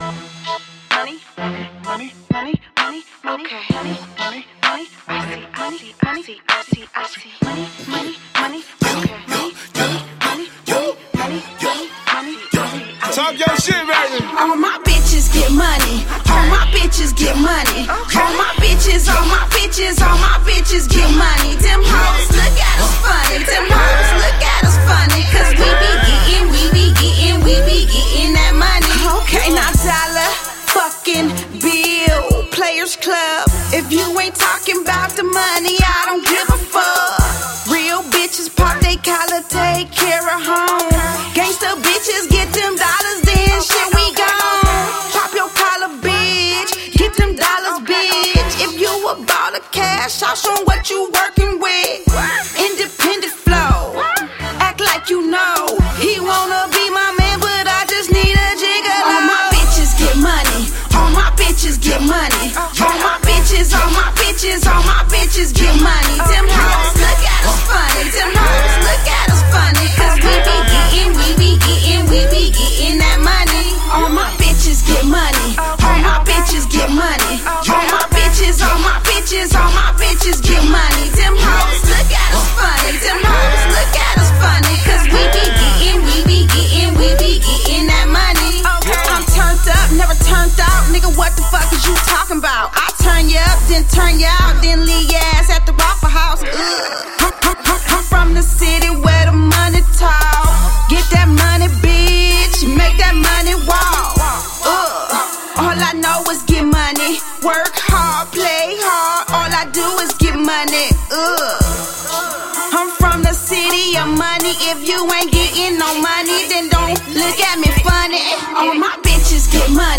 Hiphop
Description : CLUB BANGER